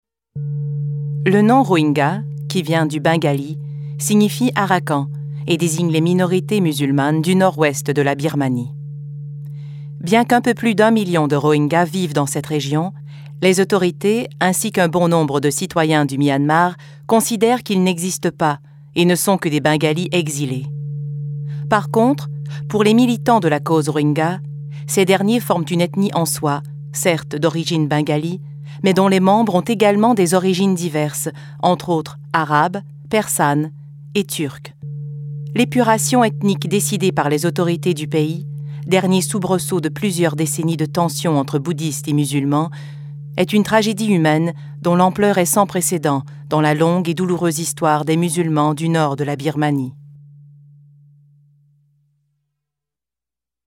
Narration - FR